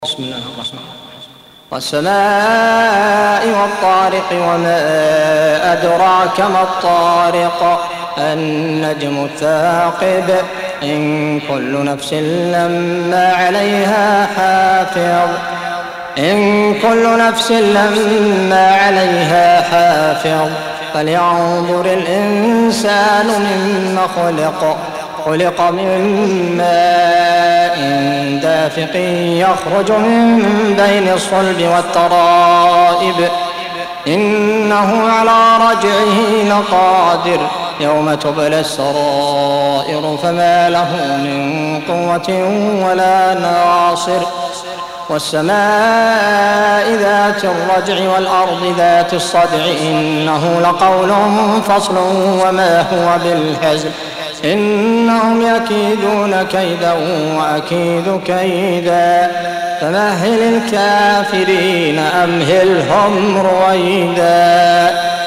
86. Surah At-T�riq سورة الطارق Audio Quran Tarteel Recitation
Surah Repeating تكرار السورة Download Surah حمّل السورة Reciting Murattalah Audio for 86.